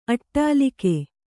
♪ aṭṭālike